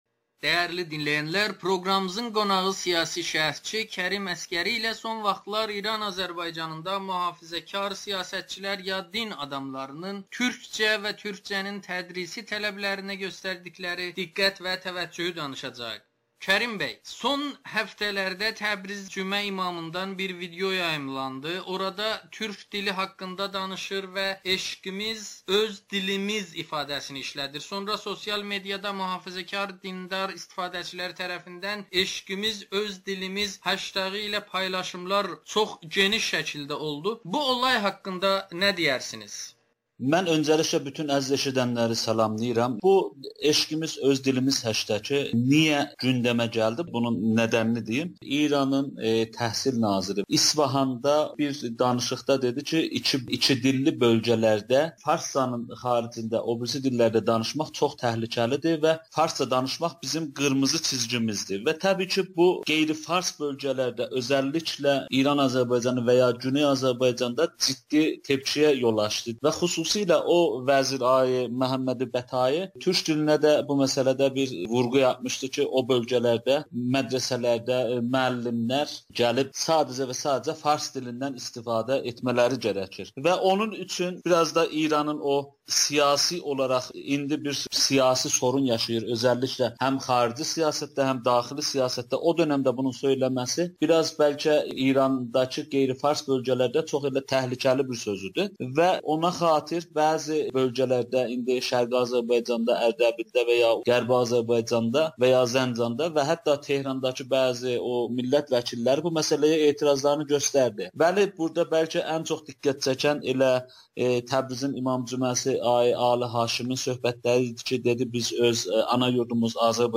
‘Eşqimiz öz dilimiz’ deyən Təbriz cümə imamının məqsədi nədir? [Audio-Müsahibə]